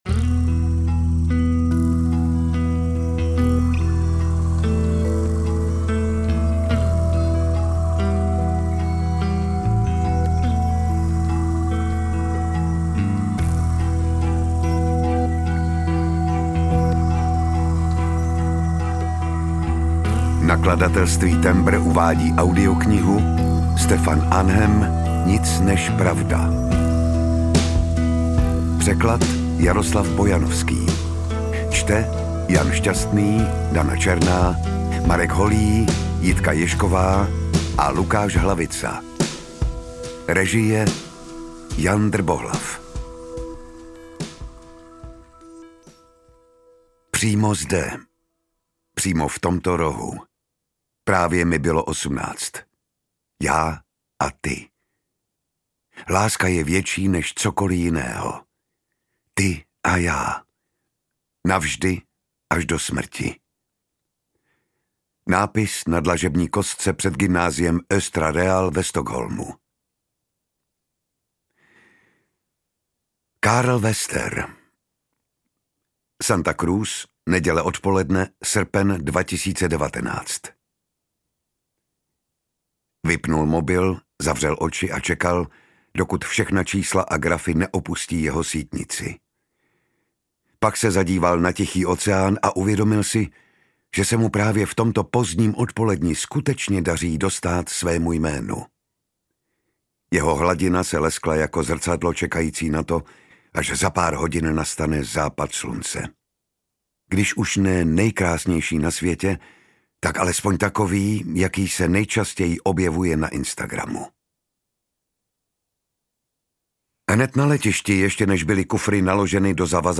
audiokniha_nic_nez_pravda_ukazka.mp3